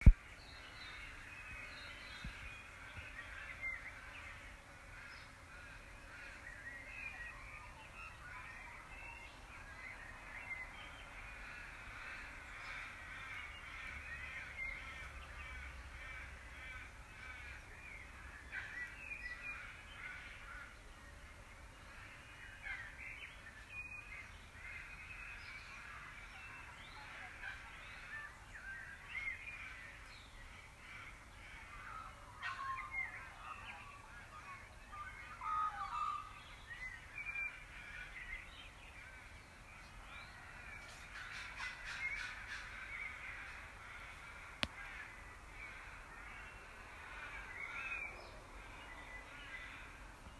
If you need some morning bird calls, open the below link: